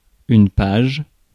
Ääntäminen
IPA: /paʒ/